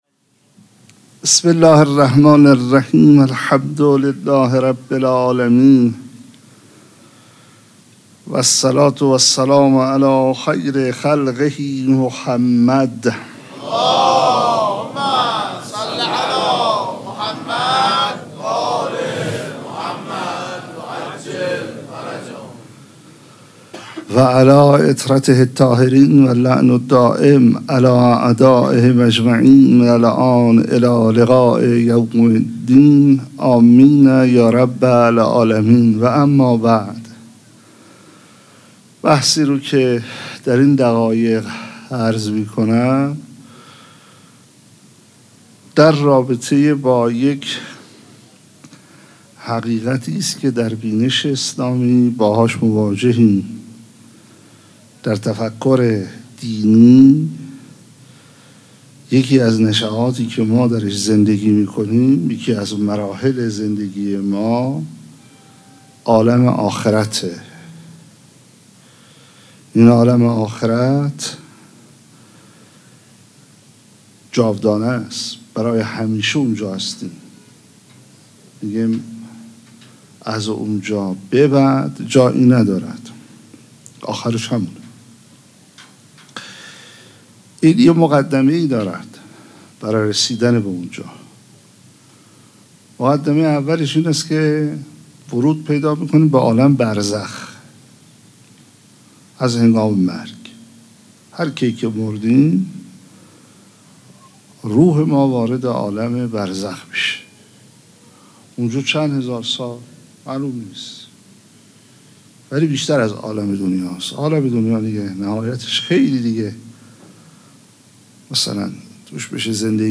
سخنرانی
سه شنبه 6 تیر ۱۴۰2 |8 ذی‌الحجه ۱۴۴4حسینیه ریحانة‌الحسین (سلام‌الله‌علیها)